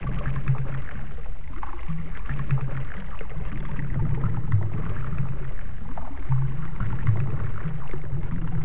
lava1.wav